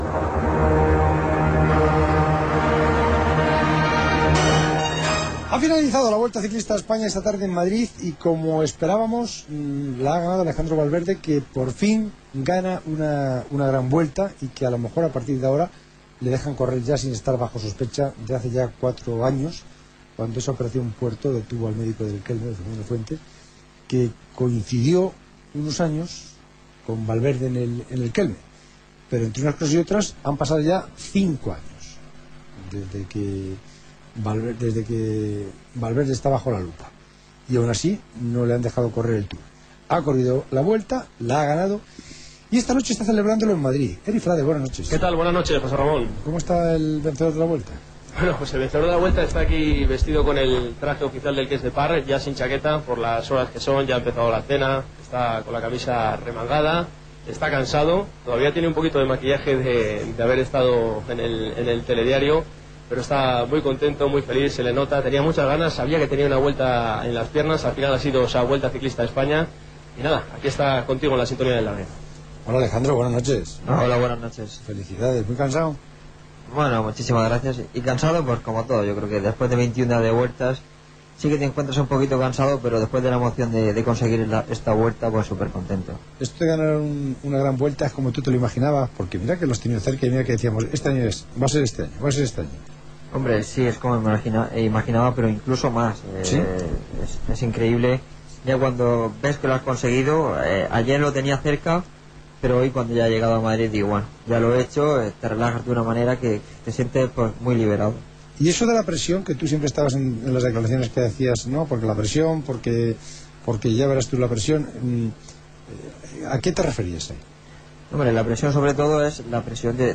El murciano hizo un alto en la cena de la victoria, en el madrileño Restaurante Sula, para atender los micrófonos de «El Larguero».